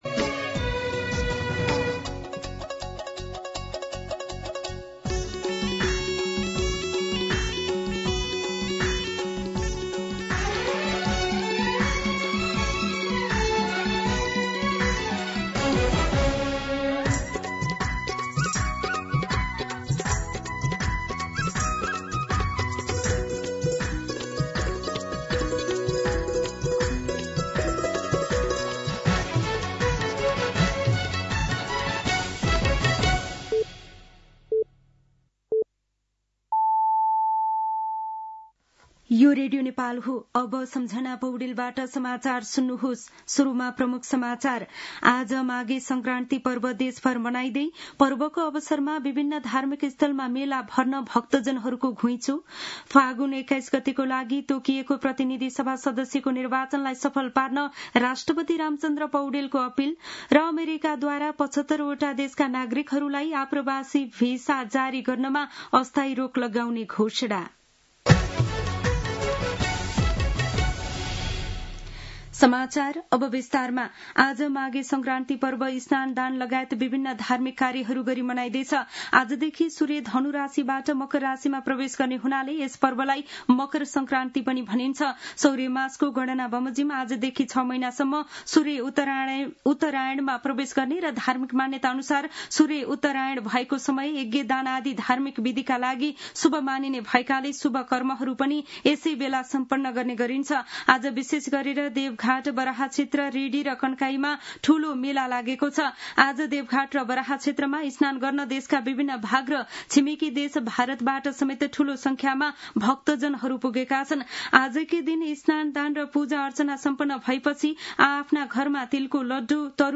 दिउँसो ३ बजेको नेपाली समाचार : १ माघ , २०८२
3-pm-Nepali-News-4.mp3